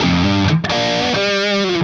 AM_HeroGuitar_130-G02.wav